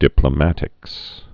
(dĭplə-mătĭks)